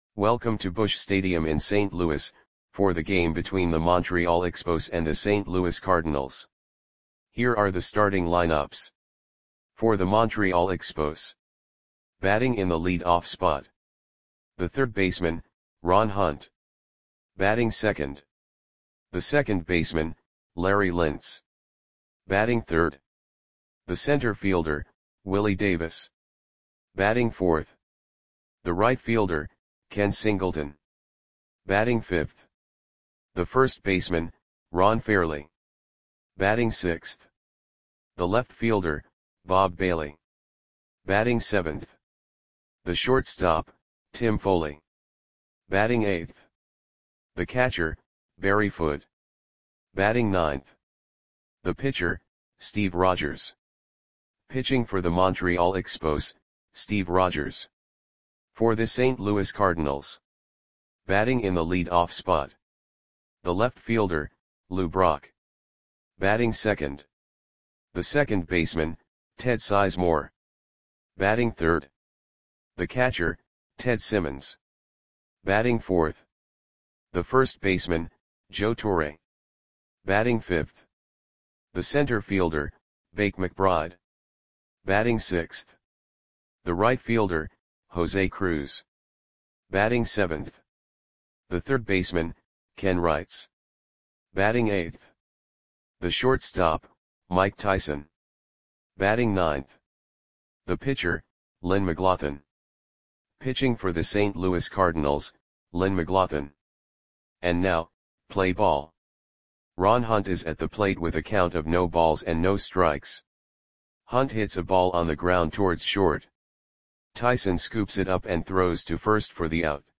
Lineups for the St. Louis Cardinals versus Montreal Expos baseball game on June 22, 1974 at Busch Stadium (St. Louis, MO).
Click the button below to listen to the audio play-by-play.